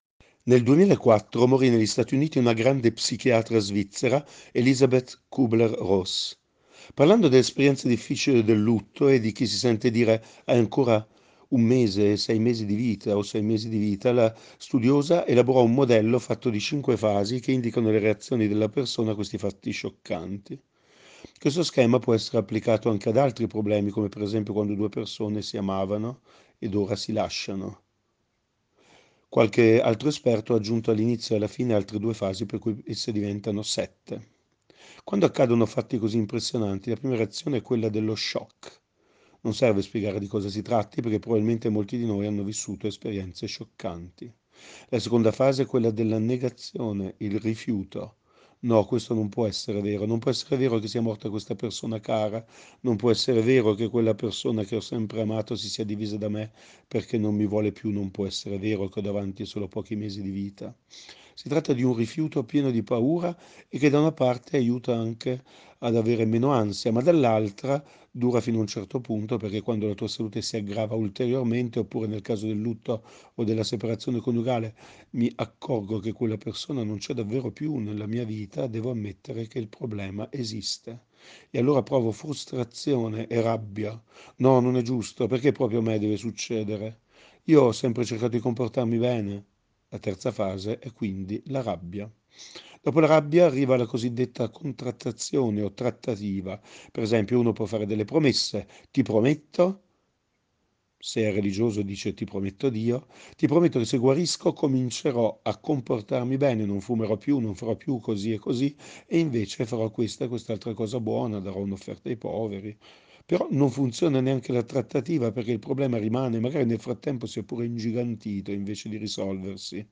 Meditazione Domenica 05/03/2023 – Parrocchia di San Giuseppe Rovereto